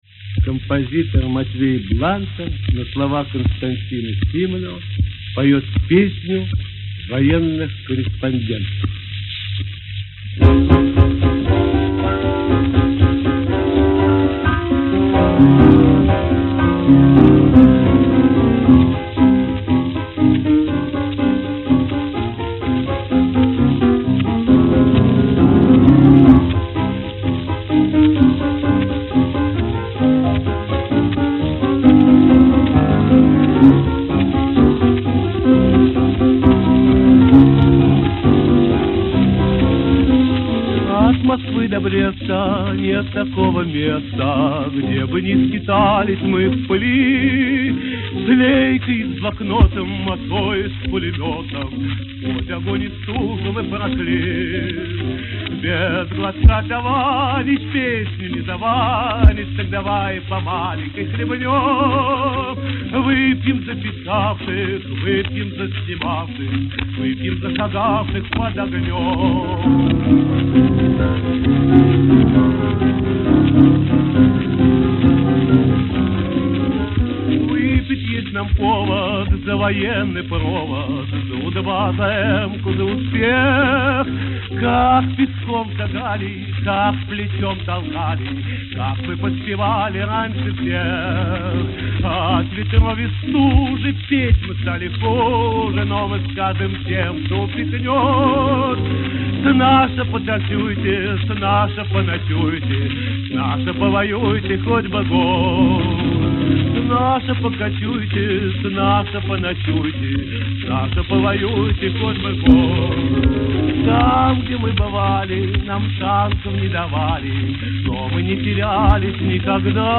Фрагмент Берлинского концерта 7 мая 1945 года.